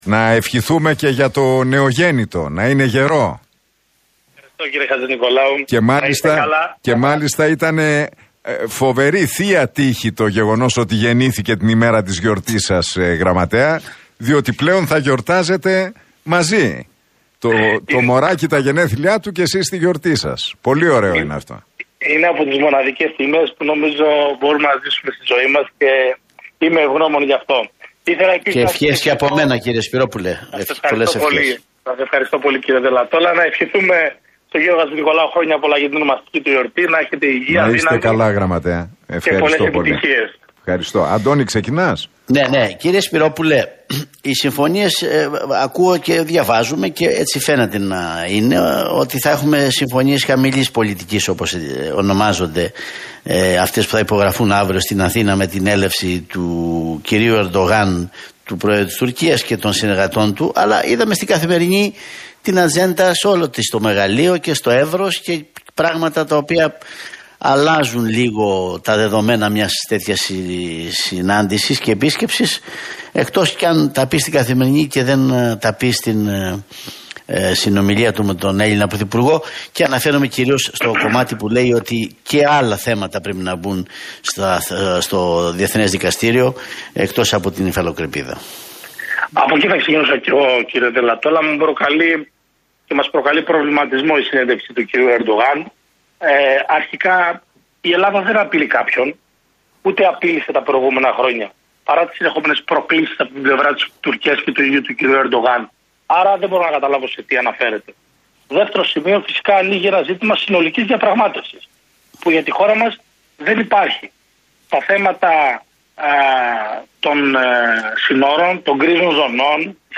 Ο Γραμματέας του ΠΑΣΟΚ – ΚΙΝΑΛ, Ανδρέας Σπυρόπουλος, μίλησε στην εκπομπή του Νίκου Χατζηνικολάου